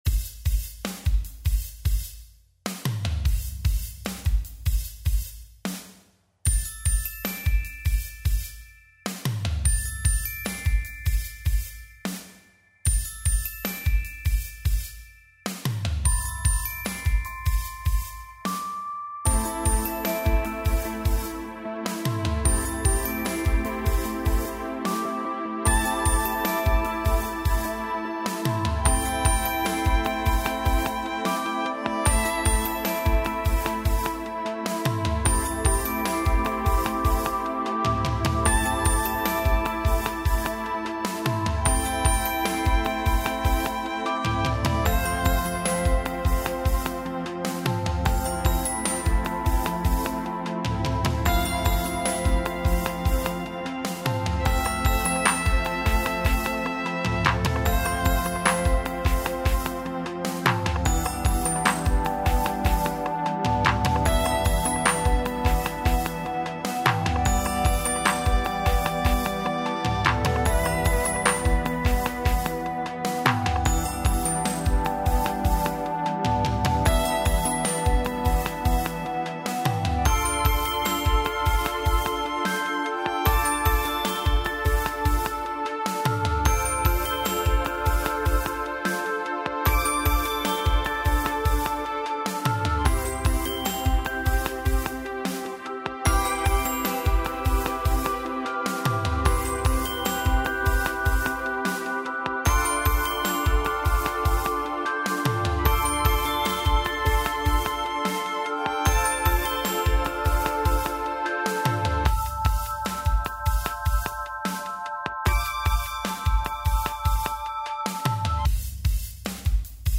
Home > Music > Beats > Smooth > Medium > Dreamy